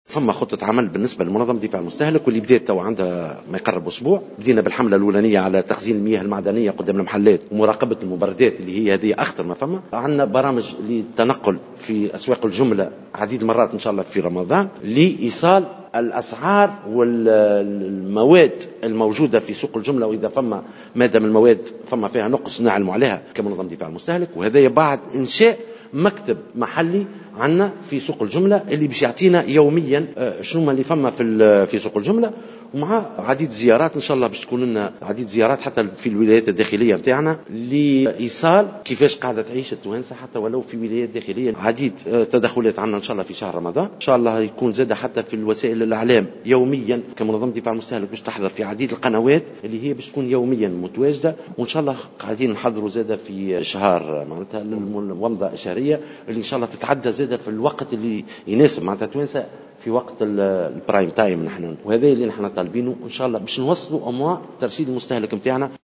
تصريح لمراسلتنا